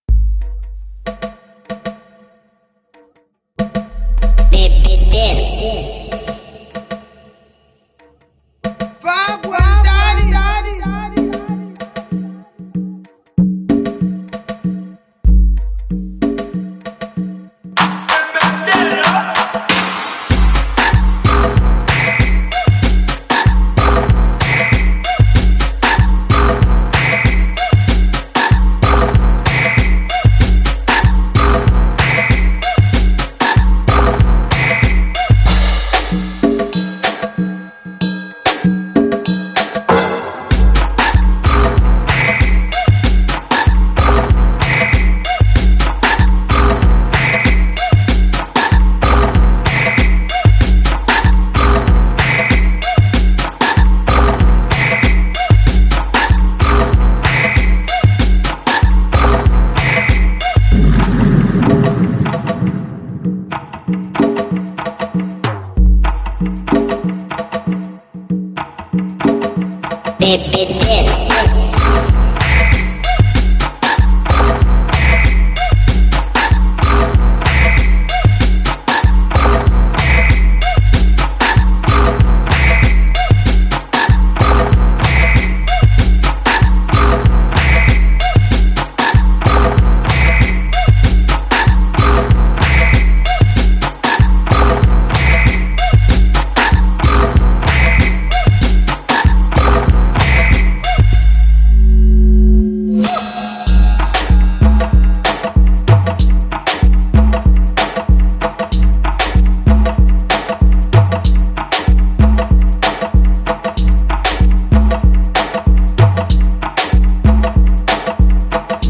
Música sincera, inadulterada, instintiva